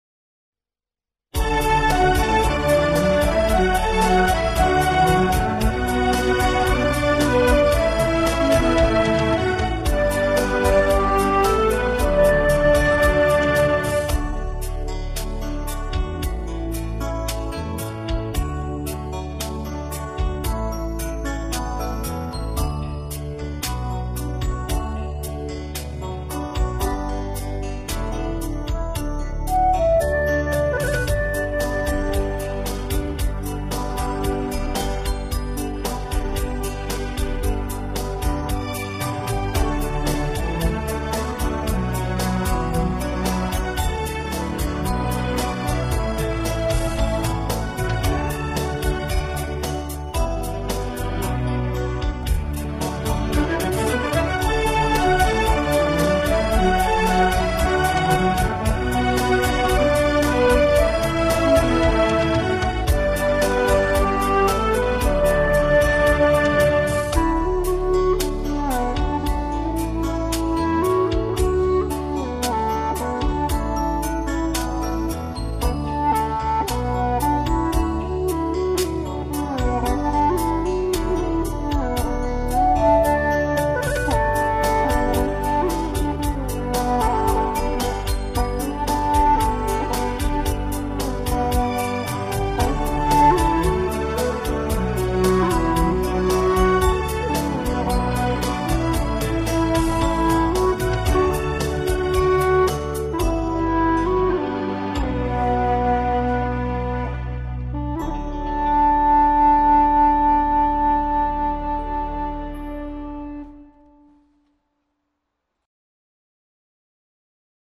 调式 : D 曲类 : 民族